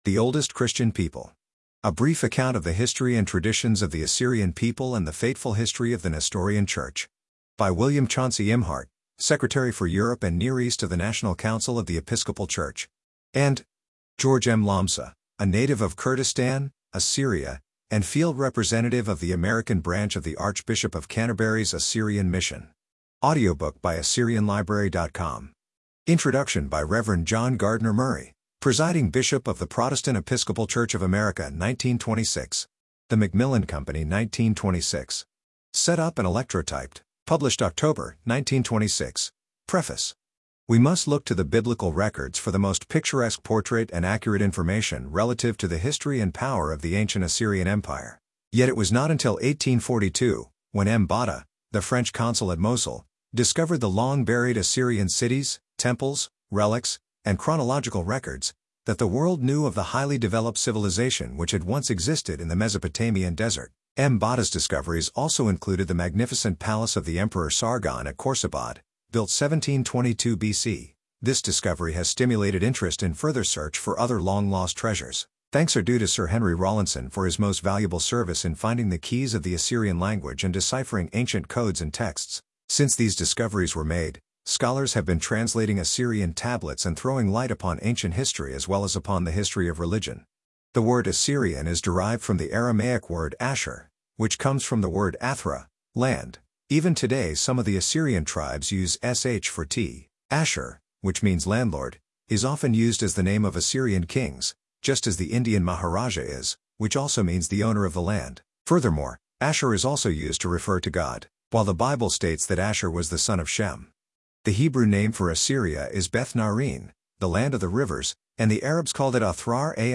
AUDIO BOOKS
Computer Natural Voice